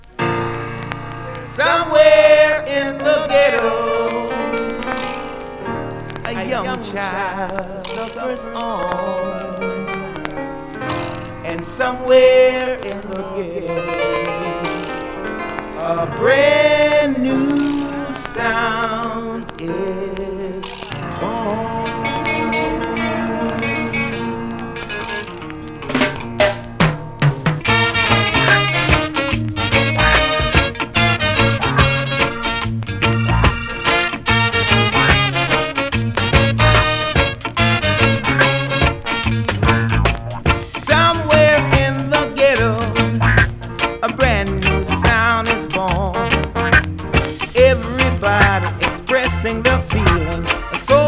7"/Vintage-Reggae, Roots